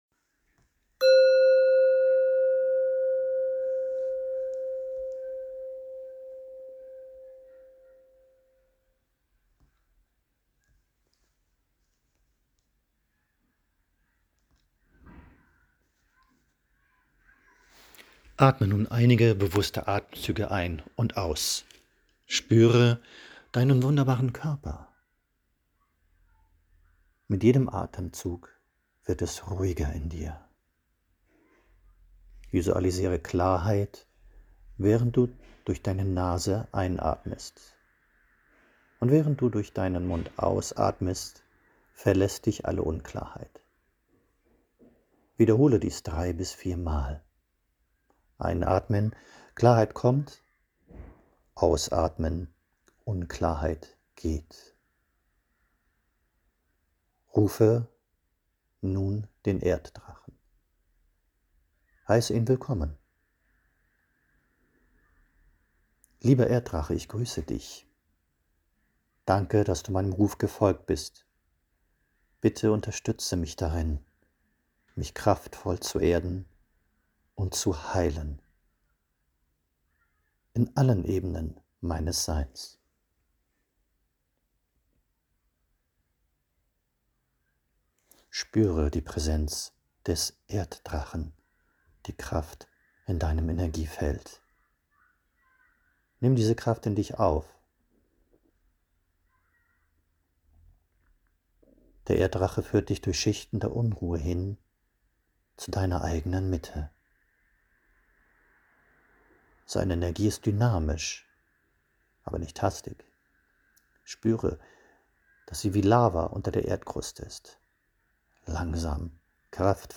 Meditation - Erdung mit dem Erddrachen